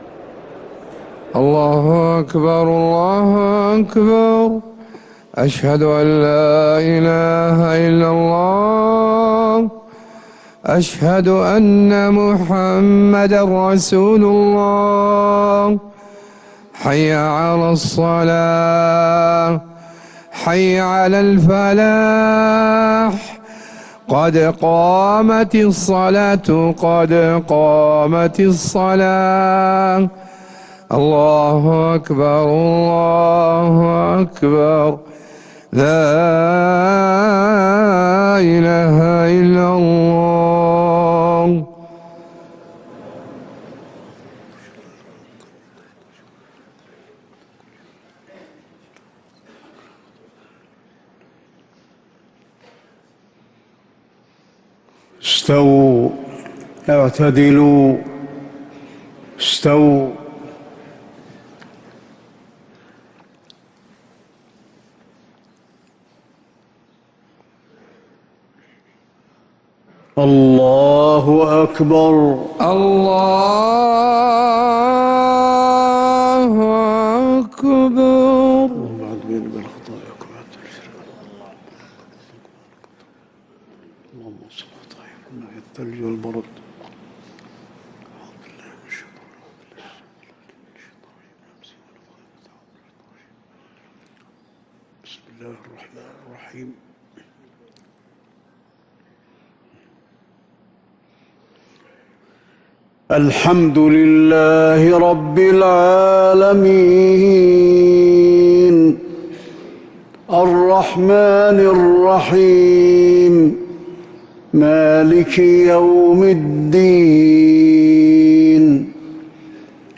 Haramain Salaah Recordings: Madeenah Fajr - 15th January 2026
Madeenah Fajr - 15th January 2026